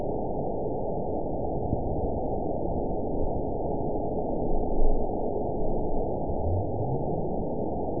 event 920533 date 03/28/24 time 22:06:15 GMT (1 year, 2 months ago) score 9.36 location TSS-AB03 detected by nrw target species NRW annotations +NRW Spectrogram: Frequency (kHz) vs. Time (s) audio not available .wav